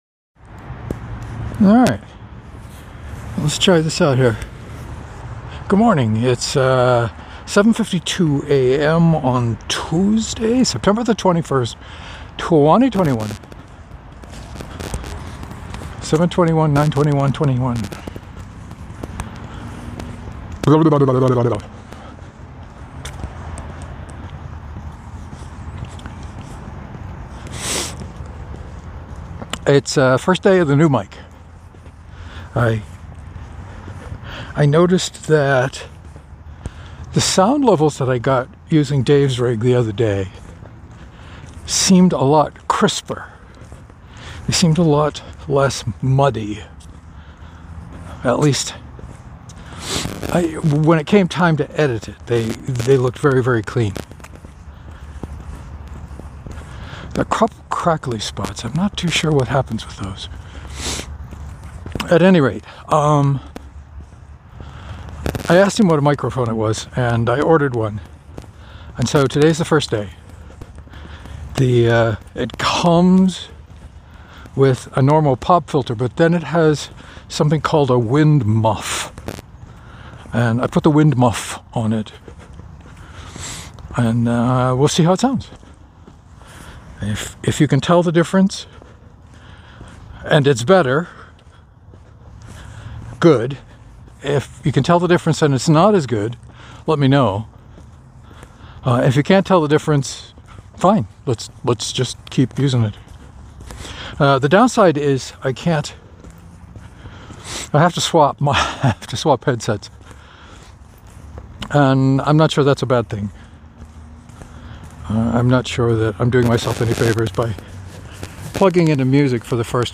First day on the new lavalier mic with actual wind screen instead of a piece of foam held on with a rubber band.
New microphone is clearer and louder.
There were some pops and crackles at the beginning that had mostly stopped by the end of the walk.